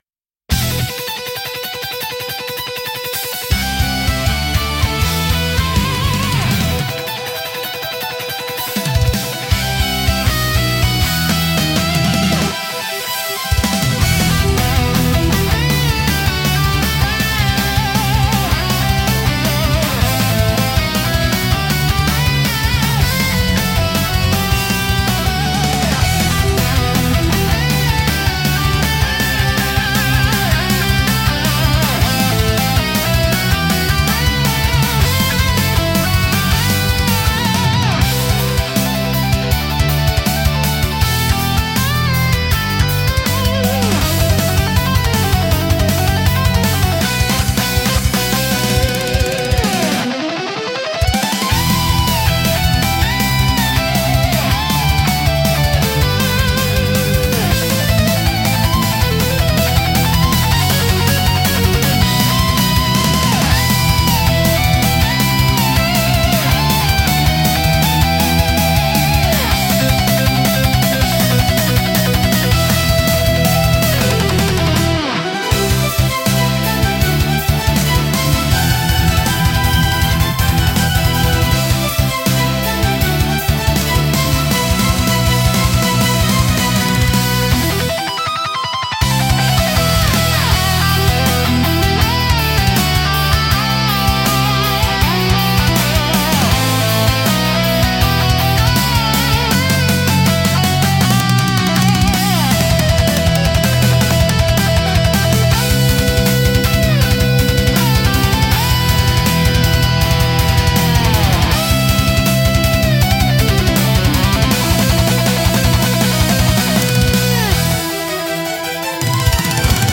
BGMとしては、ゲームや映像の戦闘シーンに最適で、激しいアクションと感情の高まりを盛り上げます。